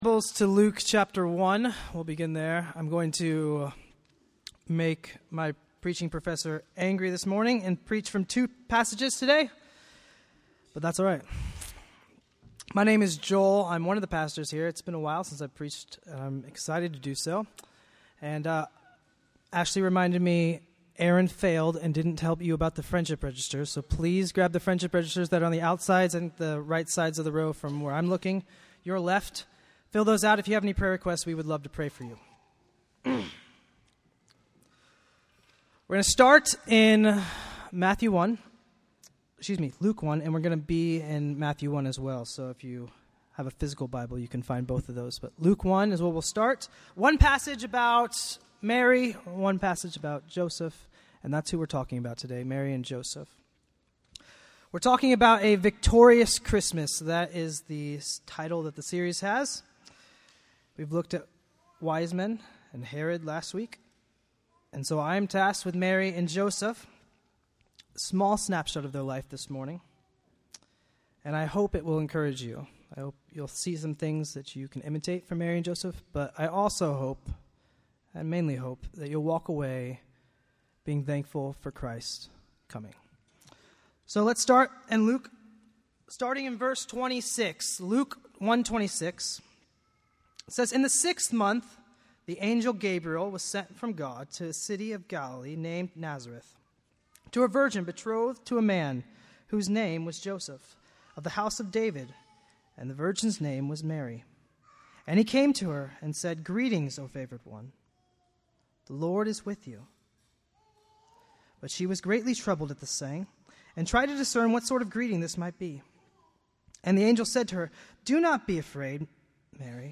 Bloomington Bible Church Sermons